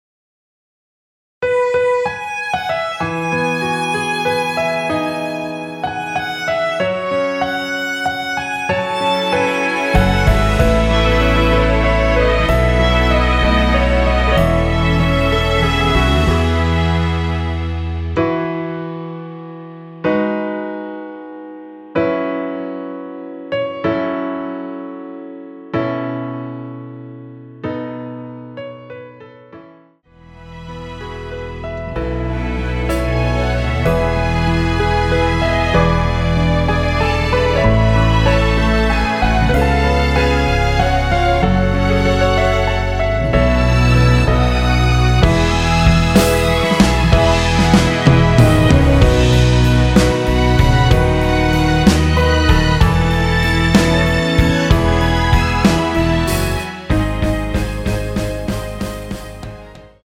원키에서(+5)올린 1절후 후렴으로 진행되는 MR입니다.
앞부분30초, 뒷부분30초씩 편집해서 올려 드리고 있습니다.
중간에 음이 끈어지고 다시 나오는 이유는